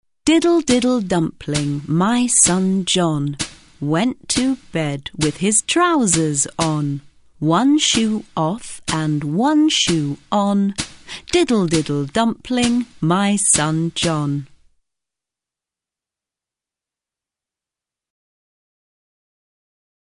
Hezká dětská rýmovačka.